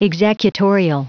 Prononciation du mot executorial en anglais (fichier audio)